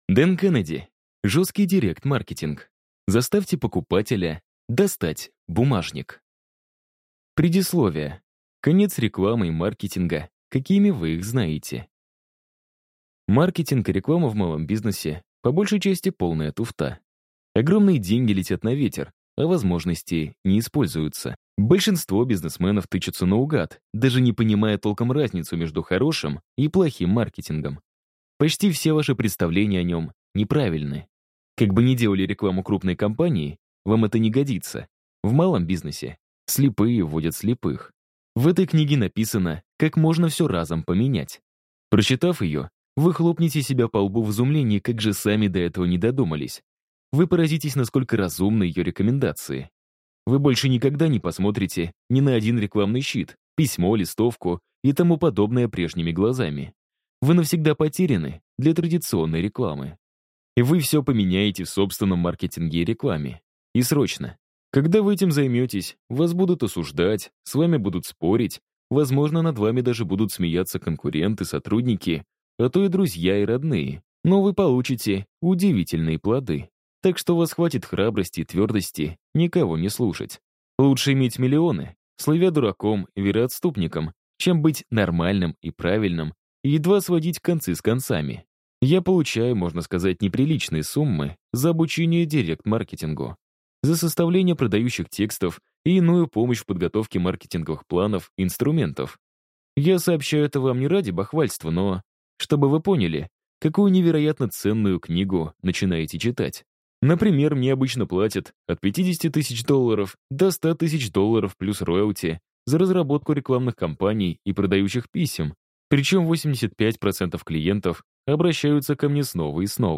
Аудиокнига Жесткий директ-маркетинг | Библиотека аудиокниг